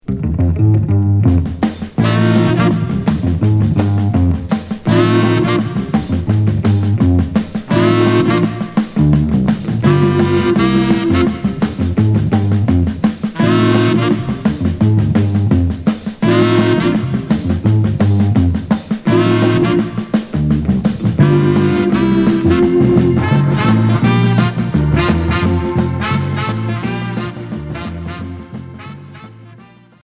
Hully gully